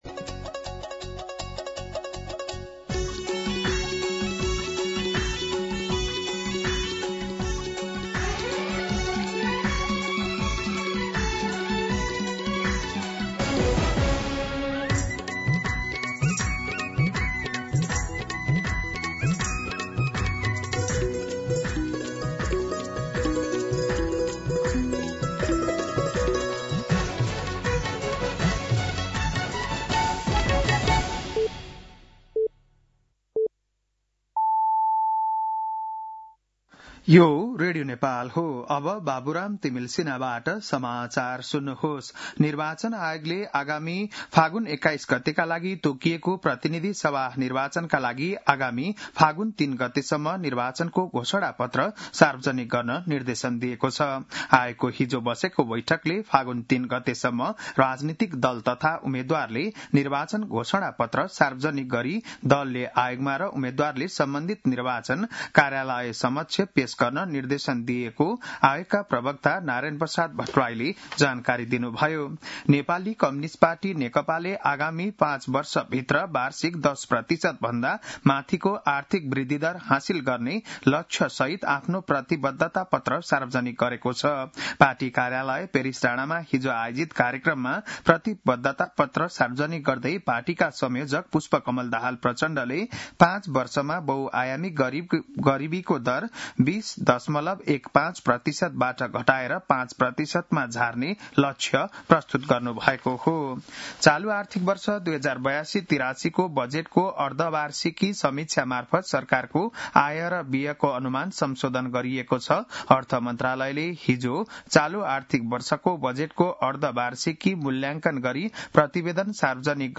बिहान ११ बजेको नेपाली समाचार : २८ माघ , २०८२